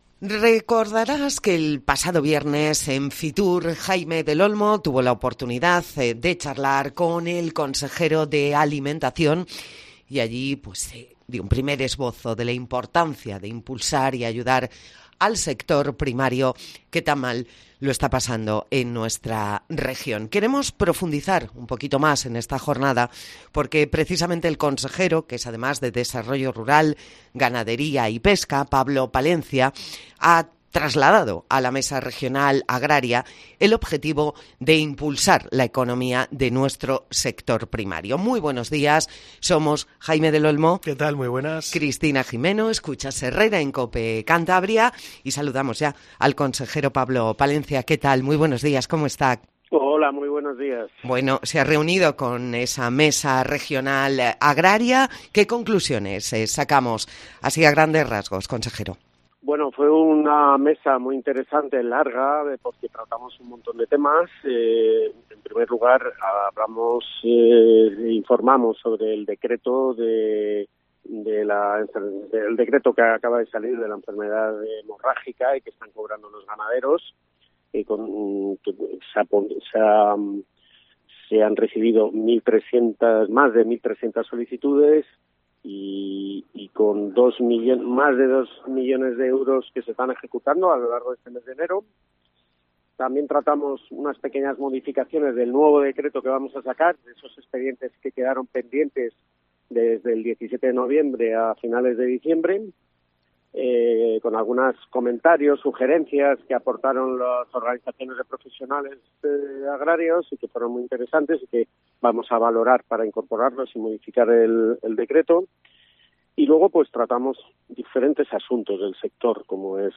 Sobre este tema habló COPE Cantabria con el consejero de Desarrollo Rural, Ganadería, Pesca y Alimentación del Gobierno de Cantabria, Pablo Palencia, en "Herrera en COPE" este miércoles 31 de enero. Puedes escuchar la entrevista pinchando debajo de la fotografía.